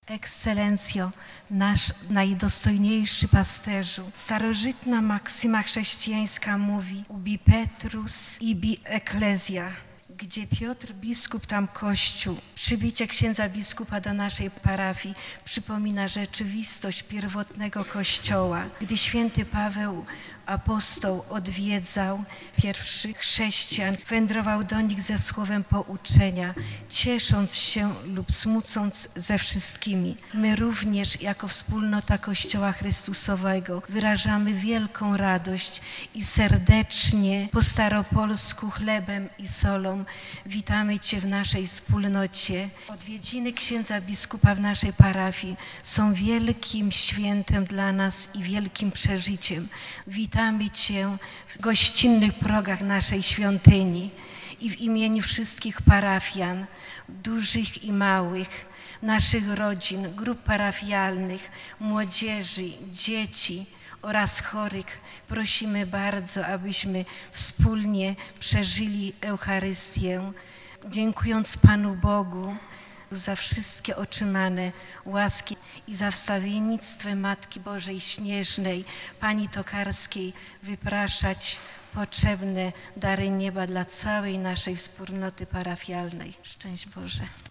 Wizytacja rozpoczęła się od uroczystego wprowadzenia do świątyni.